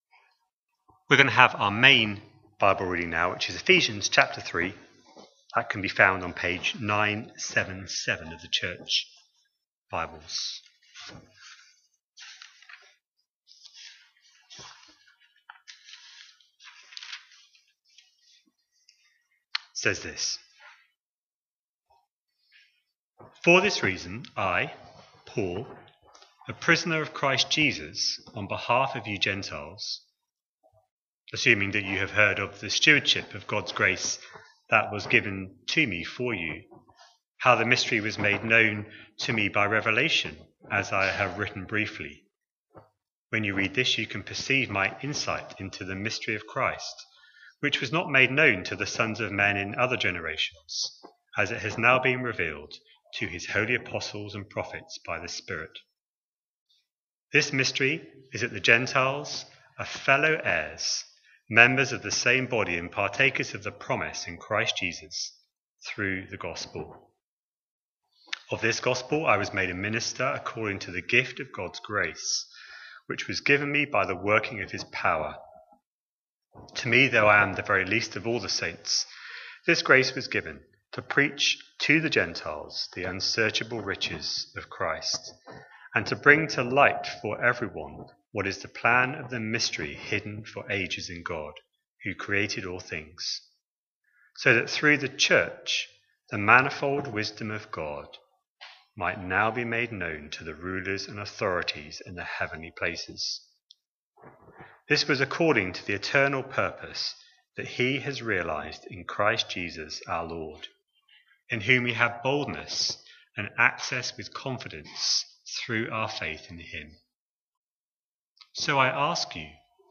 A sermon preached on 12th April, 2026, as part of our Ephesians 2026 series.